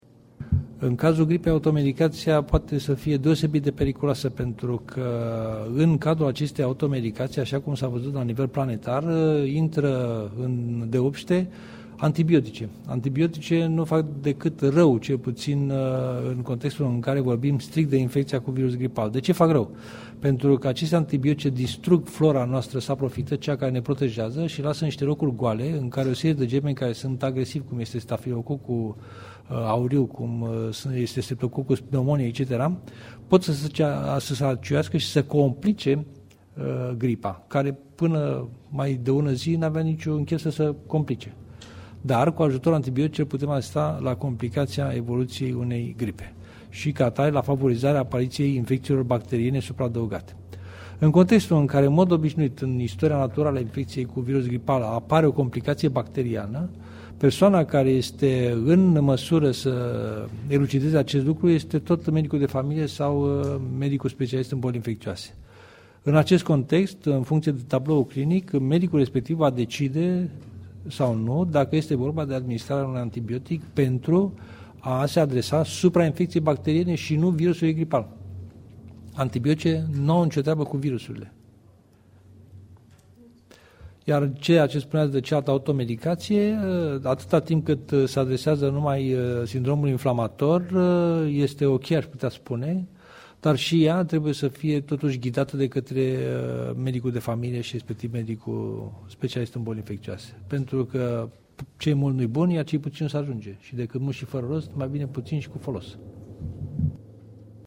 Profesor doctor Adrian Streinu Cercel-cat este de periculoasa automedicatia: